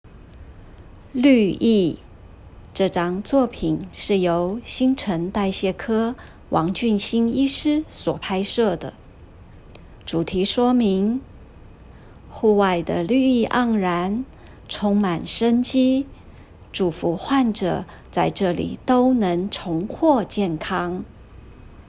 語音導覽-32綠意.m4a